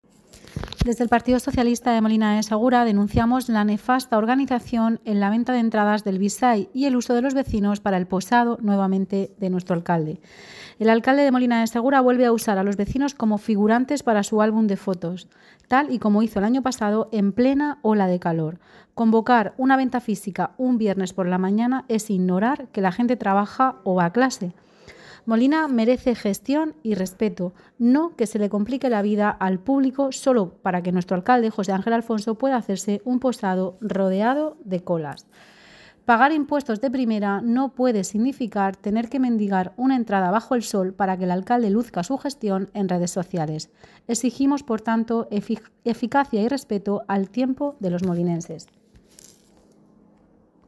La portavoz socialista, Isabel Gadea, ha sido tajante al respecto:
Isabel-Gadea-Declaraciones-Bside.mp3